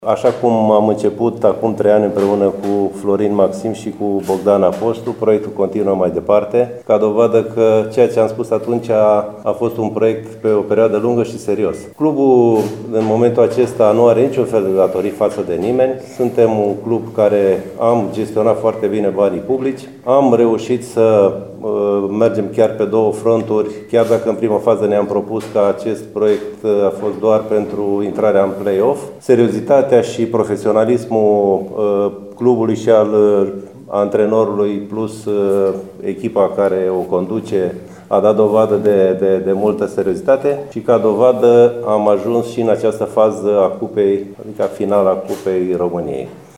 Anunţul a fost făcut, astăzi, prin intermediul unei conferinţe de presă, cu 24 de ore înainte de confruntarea cu FK Csikszereda, din penultima etapă a play-off-ului, partidă programată mâine, la ora 17:30, pe arena „Michael Klein”.